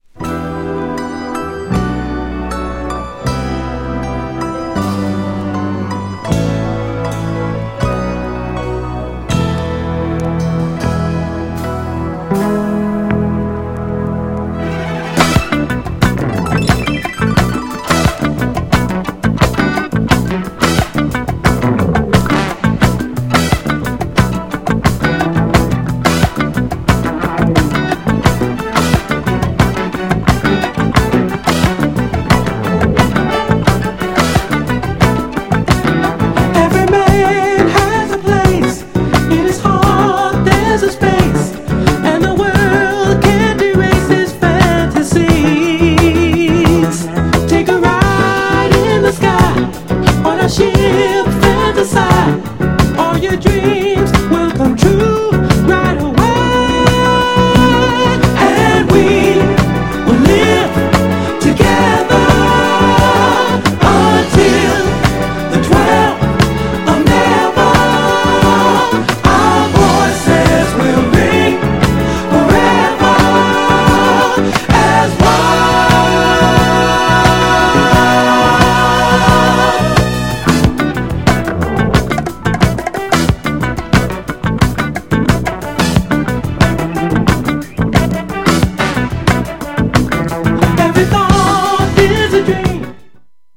GENRE Dance Classic
BPM 71〜75BPM